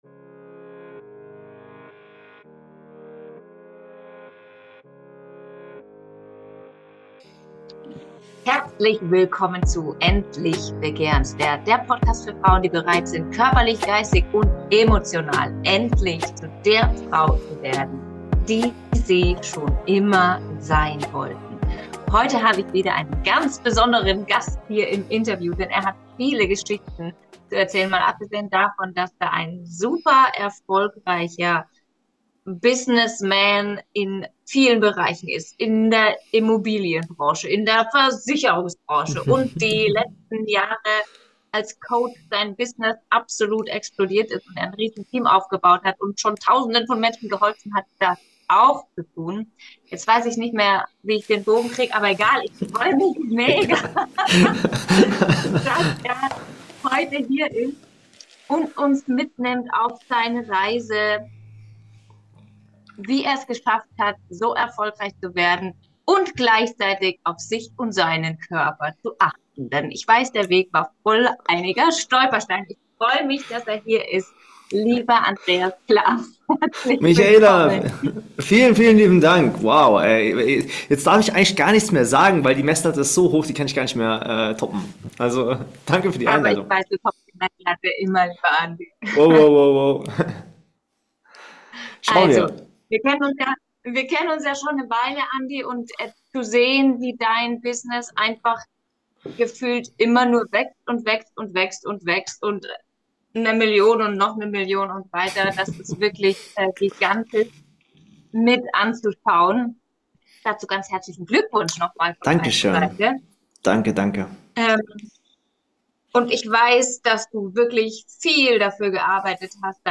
Ein Interview mit Kundengewinnungs- und Marketingexperte